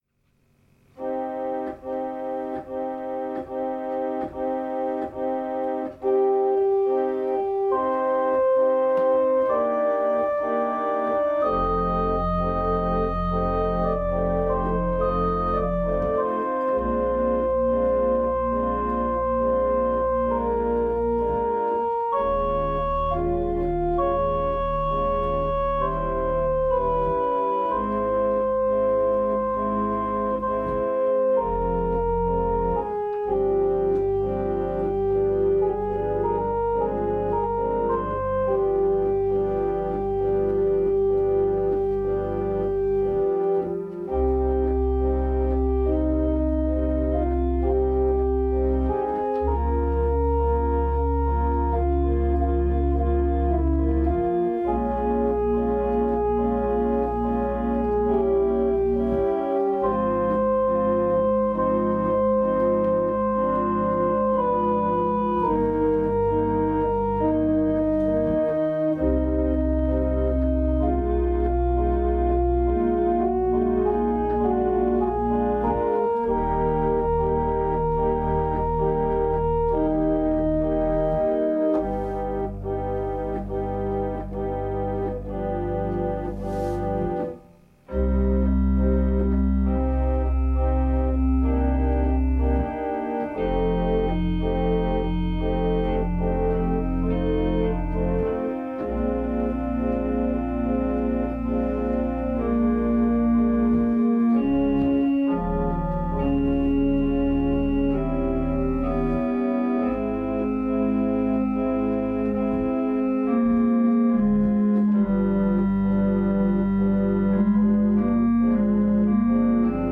Soundprogramme aus dem Internet geladen und stundenlange Versuche mit Einstellungen, Treibern etc. Die E-Orgel kommt zurück ans Stromnetz.
Solo: Klarinette 8′, Hauptmanual: Prinzipal 8’+ Gamba‘, Schwellwerk: Salicional+ Vox céleste 8′
Bei den gespielten Sücken arbeite ich häufig mit vier unterschiedlichen Klangebenen, die auf vier Tastaturen registriert und gespielt werden.